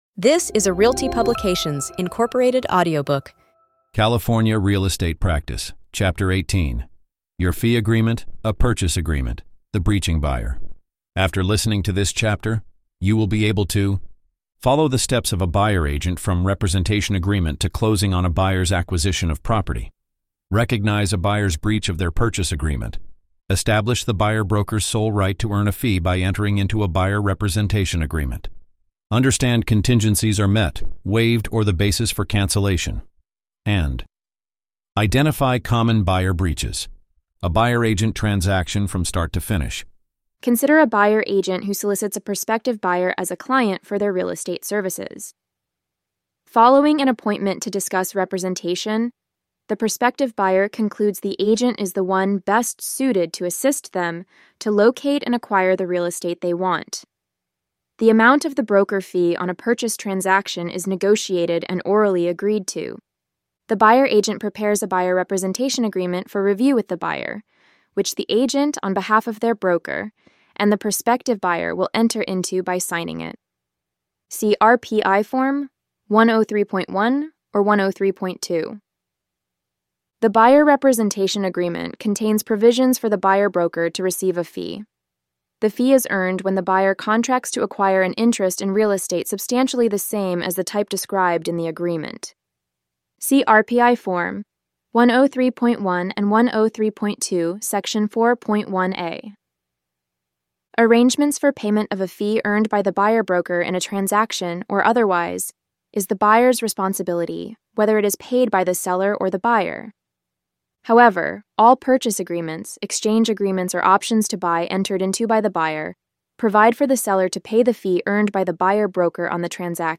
Follow along with an audio reading of this article adapted as a chapter from our upcoming Real Estate Practice course update.